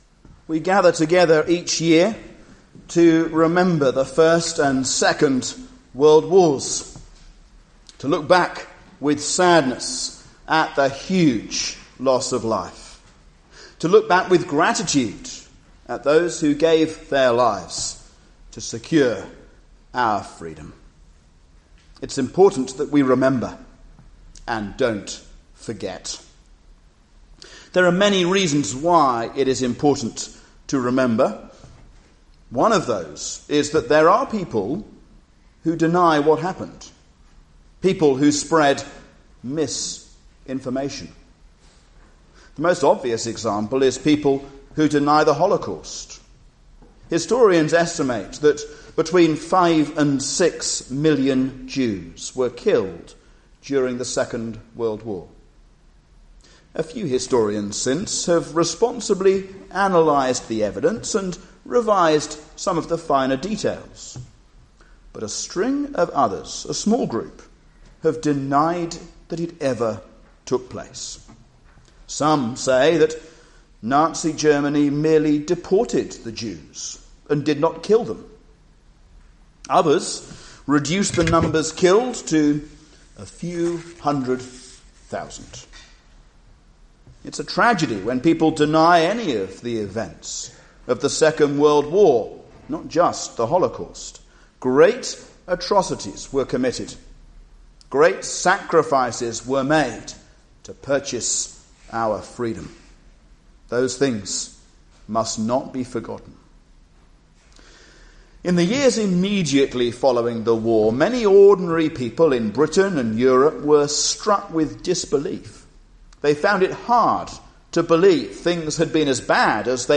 Remember When I'm Gone (Remembrance 2019), A sermon on 2 Peter 1:12-15